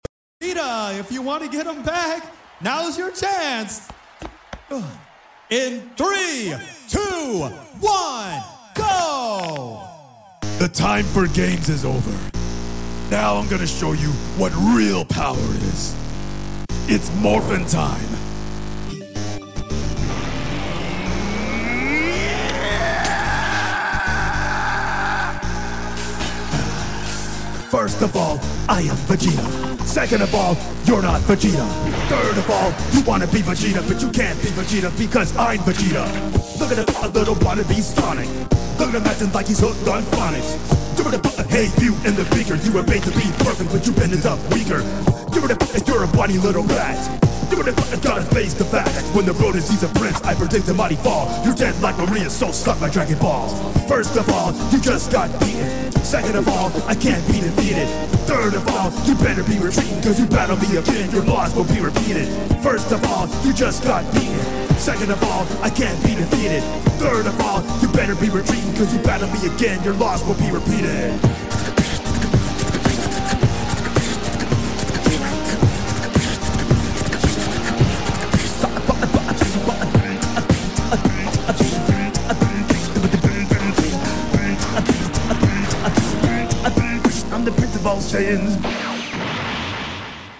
black Panthers beatbox was good